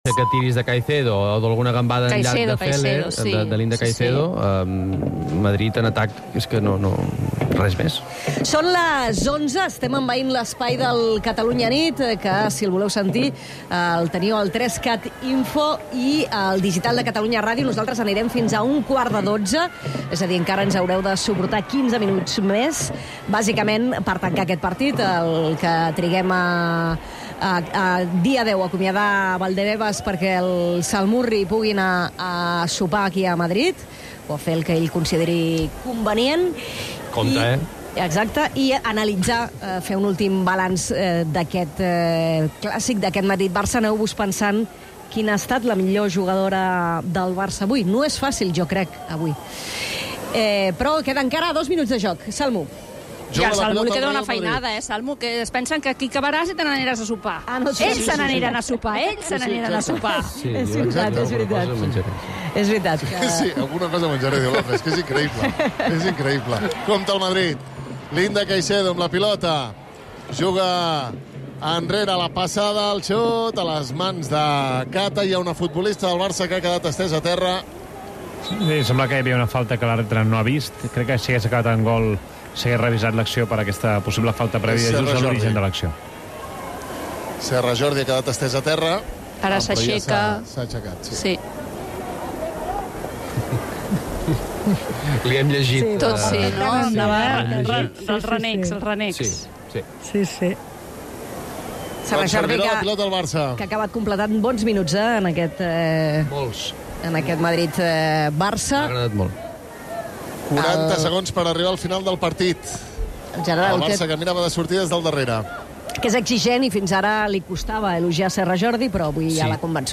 Manel Alías i Agnès Marquès dirigeixen un informatiu diari, a les 22.00, per saber i entendre les principals notícies del dia, amb el plus d'anàlisi amb el ritme pausat al qual convida la nit.